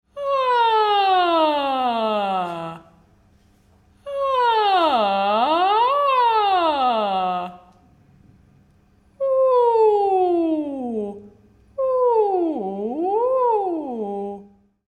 Ääniharjoituksia
Liu' uta ääntä jollakin vokaalilla ylös-alas ilman säveltasoja. Ääntely voi olla kuin huokauksia ja jäljitellä palosireenin ääntä.